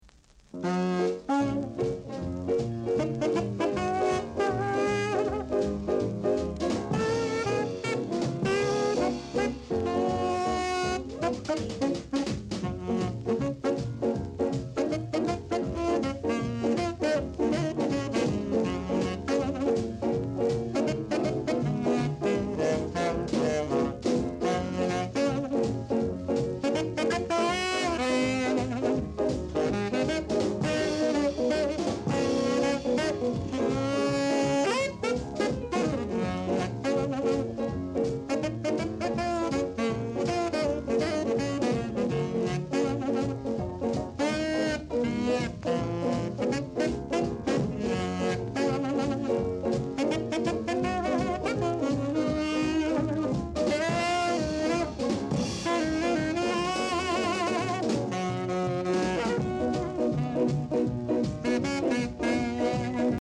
7inch / Ja / Ska Inst, Vocal Duet /
Great ska jazz inst!